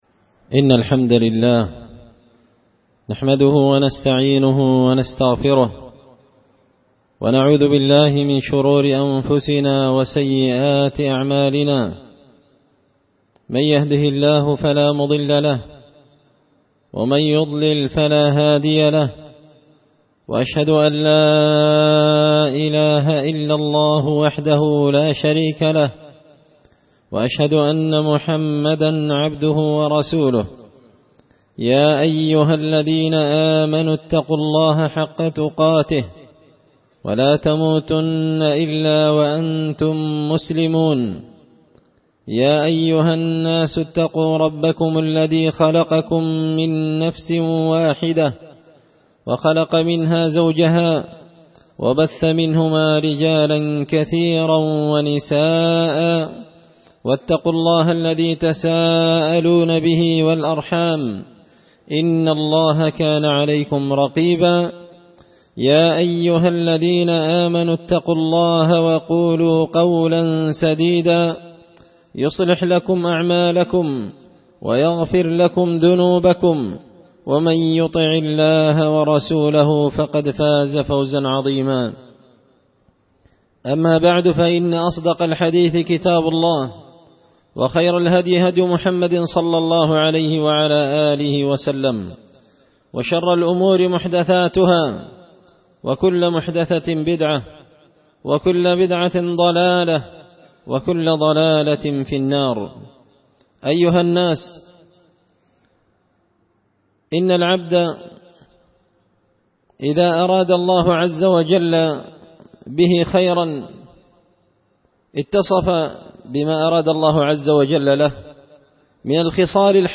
خطبة جمعة بعنوان احرص على تحصيلها خصال من أريد به الخير وفق لها
دار الحديث بمسجد الفرقان ـ قشن ـ المهرة ـ اليمن